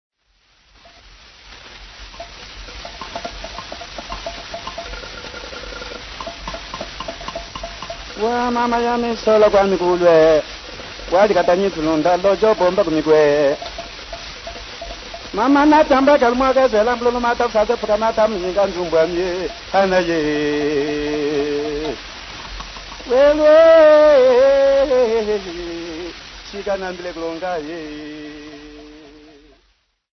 Folk Music
Field recordings
sound recording-musical
Indigenous music